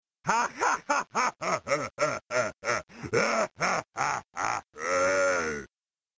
cringe-worthy laughter, no less!